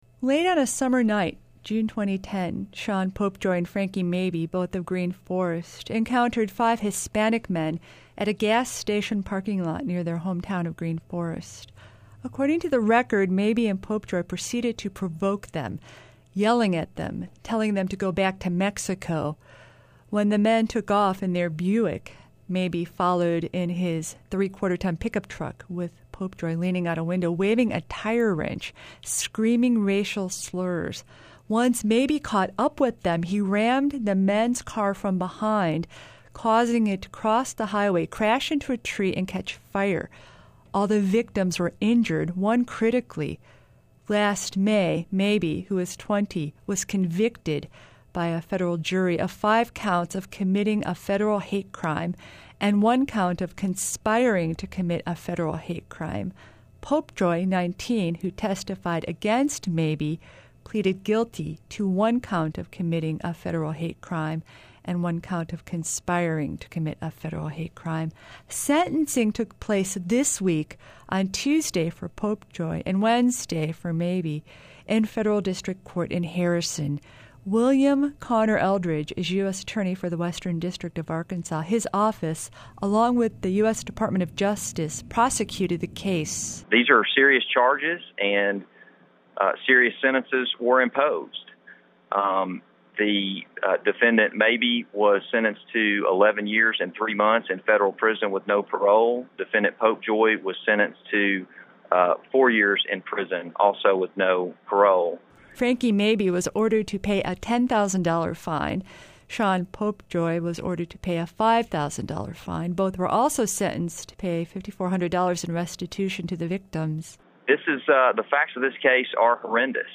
Two Green Forest men are the first to be sentenced in federal district court in Harrison, under the new Matthew Shepard and James Jr. Hate Crimes Prevention Act. We speak with U.S. District Attorney Conner Eldridge.